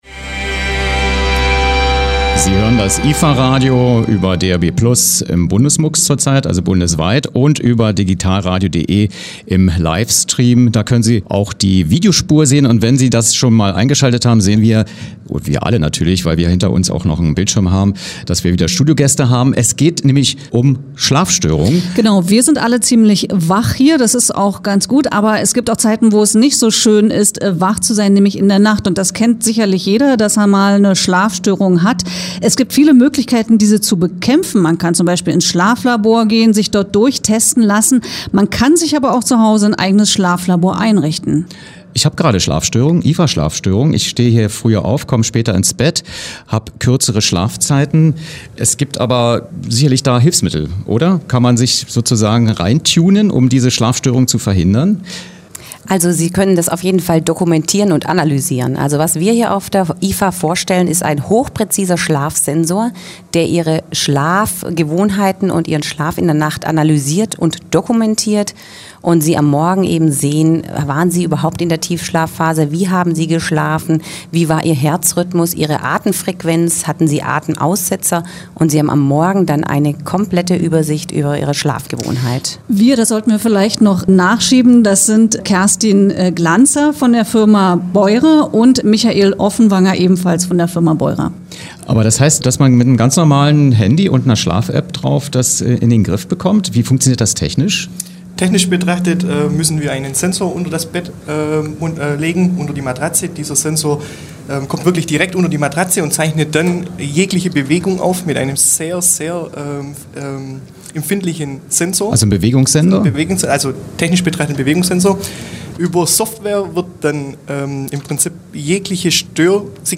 Was: Studiogespräch über eine Schlafdiagnose-Hard-/Sofware für das Smartphone
Wo: Berlin, Messegelände, Halle 2.2